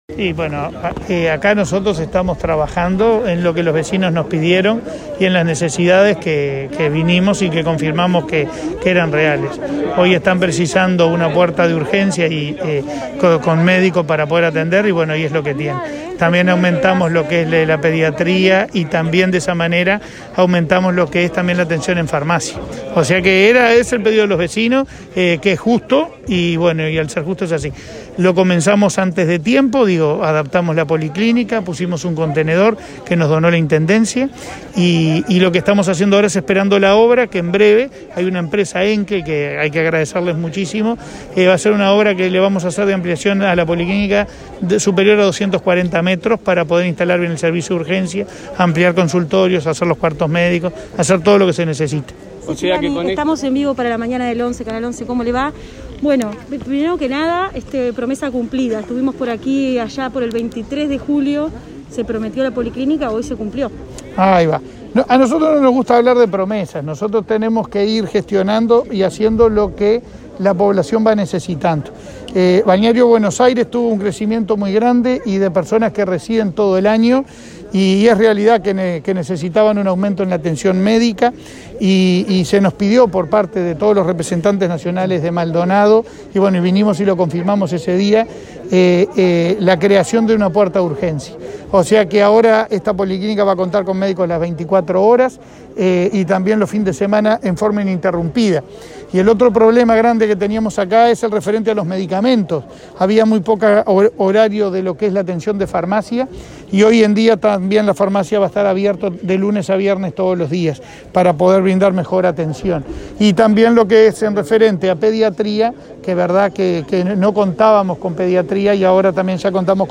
Declaraciones de prensa del presidente de ASSE, Leonardo Cipriani
Declaraciones de prensa del presidente de ASSE, Leonardo Cipriani 07/09/2021 Compartir Facebook X Copiar enlace WhatsApp LinkedIn Este martes 7, el presidente de ASSE, Leonardo Cipriani, habilitó el servicio de urgencia y la extensión horaria en otras áreas médicas de la policlínica del balneario Buenos Aires, en Maldonado, y, luego, dialogó con la prensa.